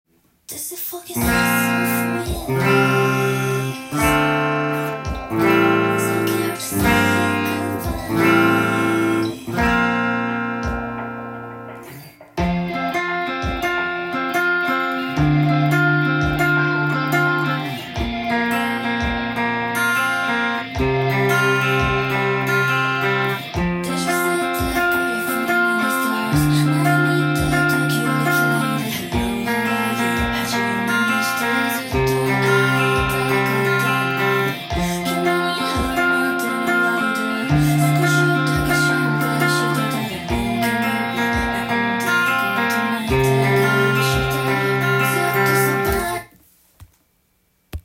完全耳コピTAB譜
音源に合わせて譜面通り弾いてみました
最初のコードがDm7以外はテンションコードになっています。
２段目からリズムがとりずらし　アルペジオがギターパートで弾かれていました。